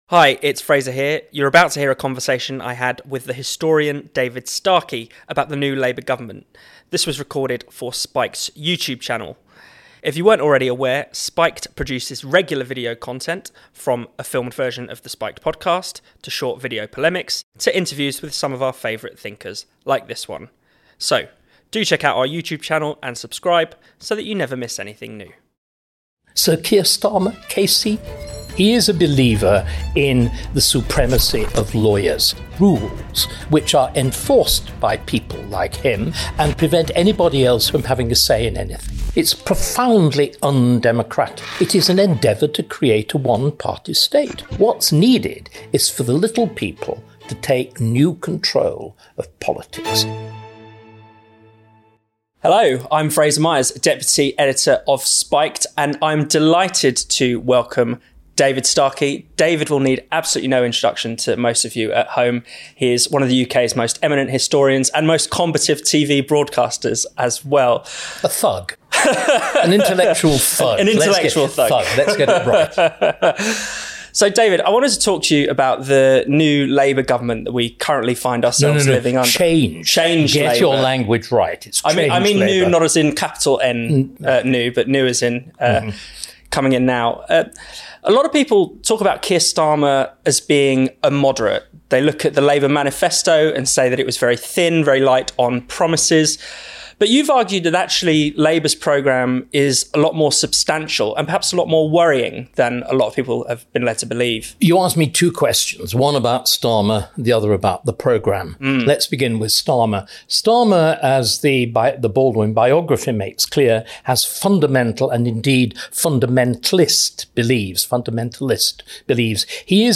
This is the audio from a video interview we just published on our YouTube channel, with historian and broadcaster David Starkey.